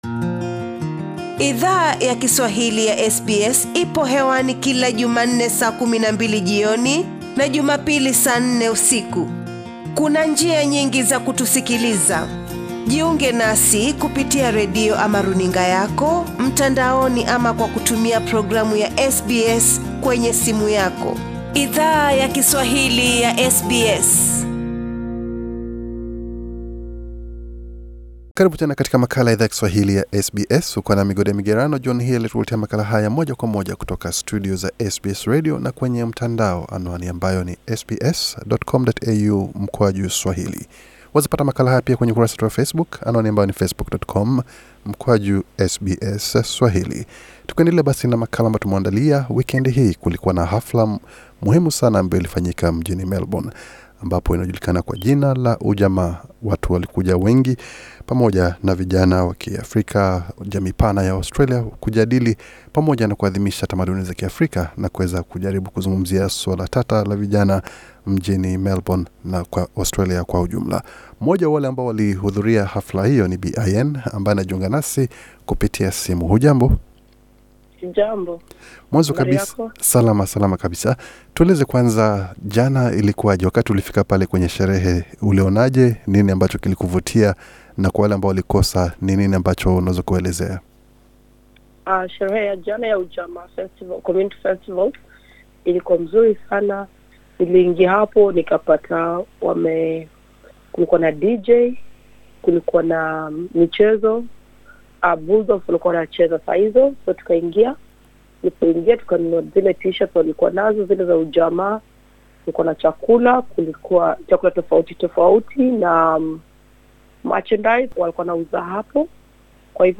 SBS Swahili ilizungumza na mmoja wa vijana aliye hudhuria tukio hilo. Katika mahojiano alizungumzia umuhimu wa tukio hilo, na alichangia pia baadhi ya pendekezo ambazo anahisi zinaweza badili gumzo inayo ongozwa na wanasiasa na vyombo vya habari ambavyo vime kuwa viki shinikiza vijana waki Afrika wa Melbourne.